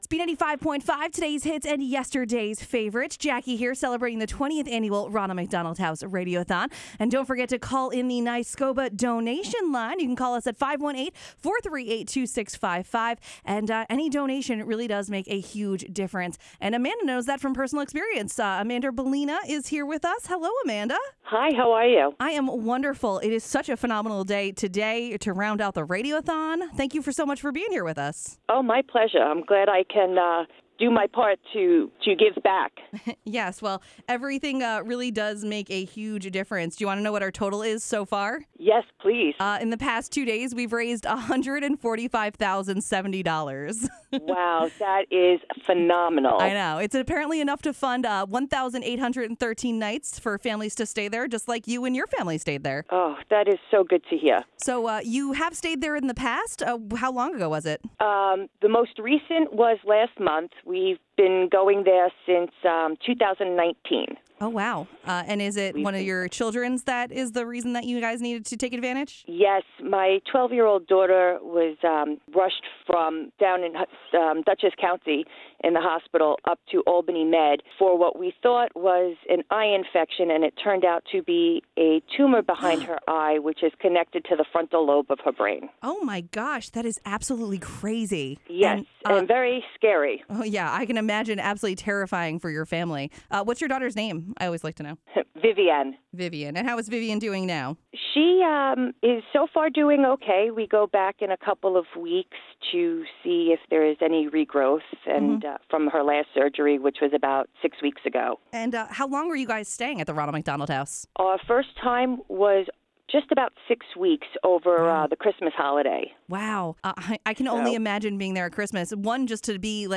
Many of our beloved guest families graciously shared their stories on the air during the 2021 Radiothon.